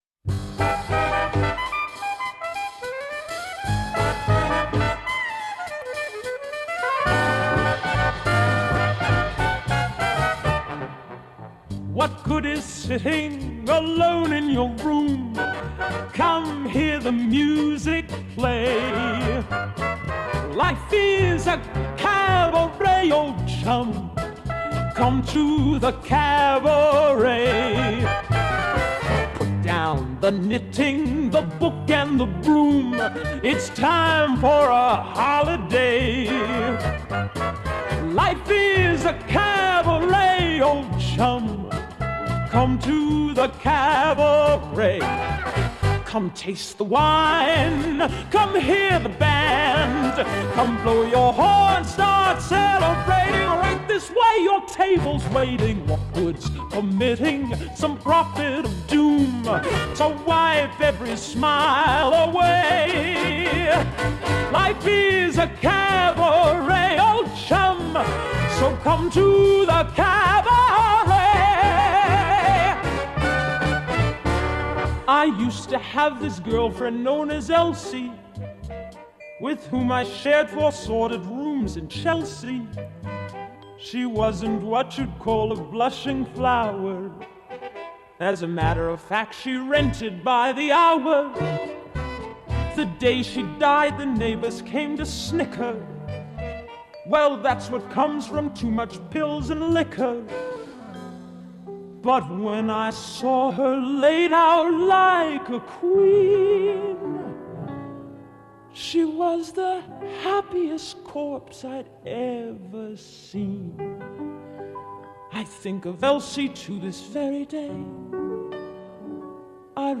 and audio in G